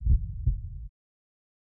心脏跳动
描述：心跳稳定。
标签： 心跳 人类 跳动 心脏 身体
声道立体声